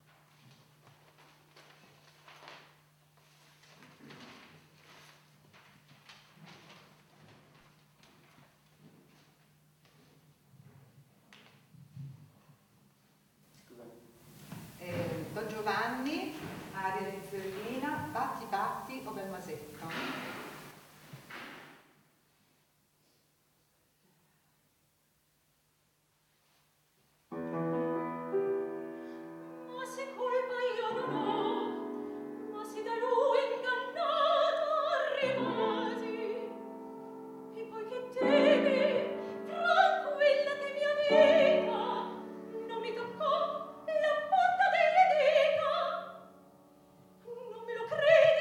aria di Zerlina
soprano
pianoforte